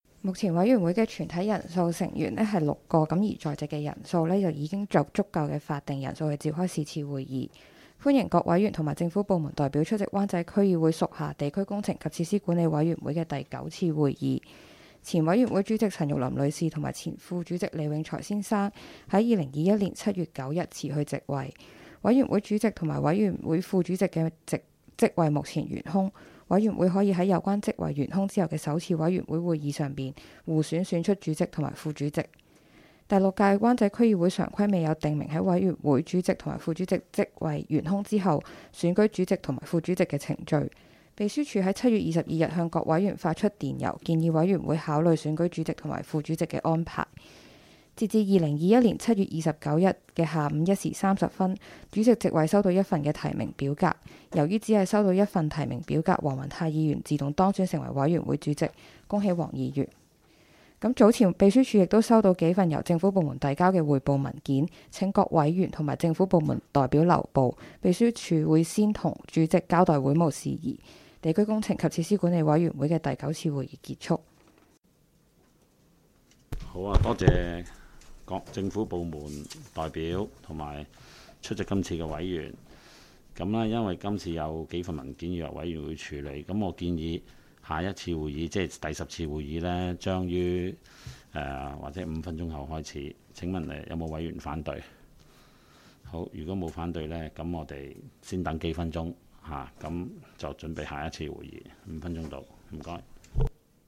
委员会会议的录音记录
地点: 香港轩尼诗道130号修顿中心21楼 湾仔民政事务处区议会会议室